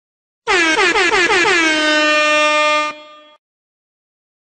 Air Horn 2 Sound Effect Free Download
Air Horn 2